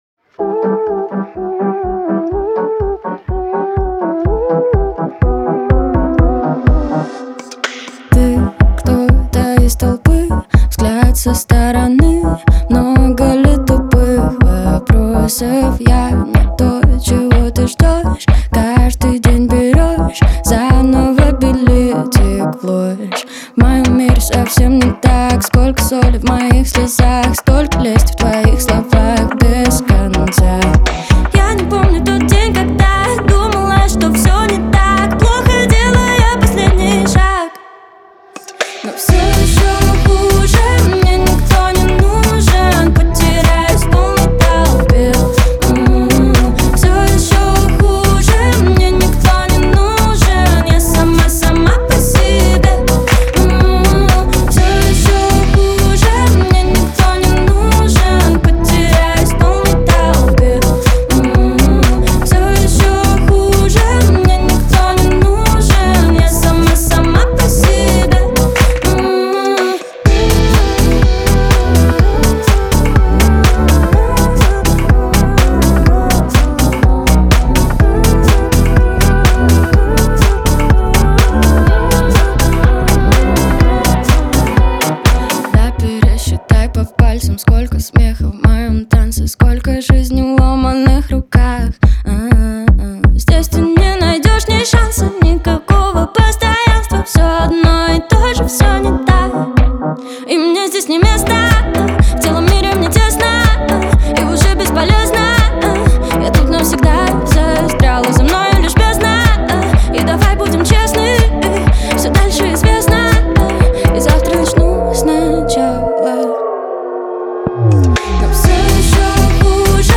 в жанре поп с элементами R&B